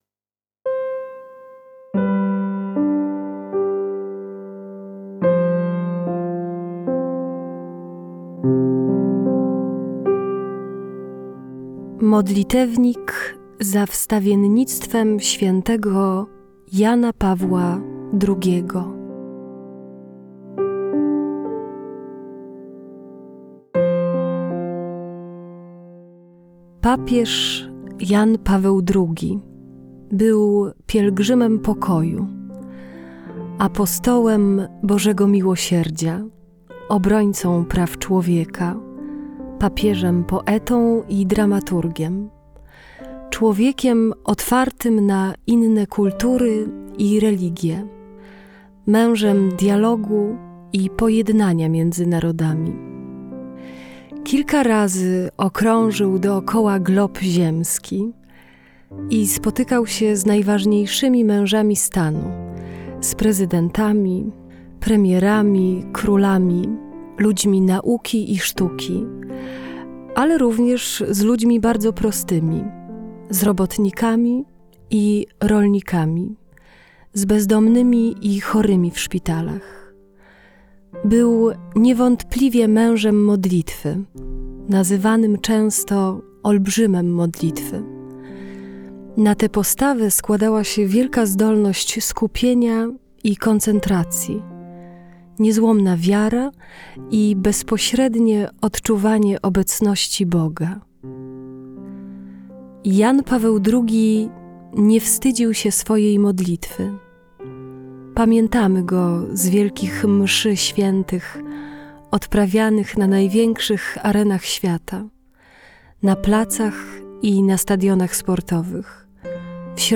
Modlitewnik za wstawiennictwem świętego Jana Pawła II czytany i śpiewany - audiobook